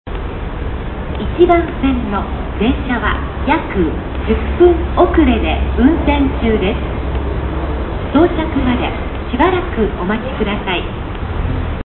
遅延放送(約10分) 初期導入の中央線から同期の総武(快速)線とこの線区まではこの遅延放送を使用していた。